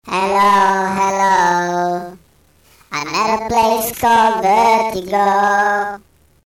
3. SUONERIE TRUE TONES